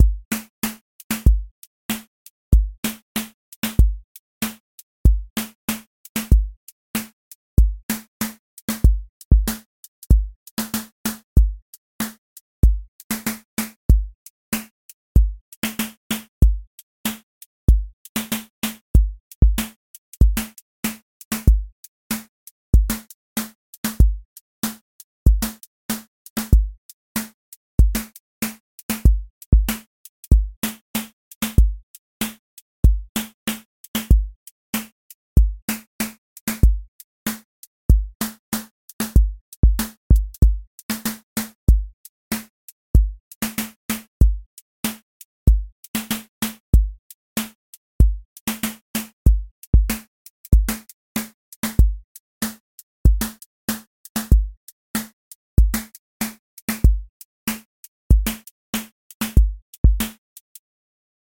QA Listening Test boom-bap Template: boom_bap_drums_a
A long-form boom bap song with recurring sections, edits within the pattern every 4 bars, clear returns, and evolving pocket over two minutes. Keep the timing anchored to sections and the bar grid, not percussion as clock